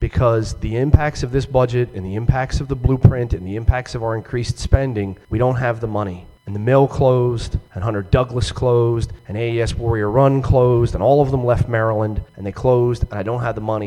Buckel Reveals County Struggles On House Floor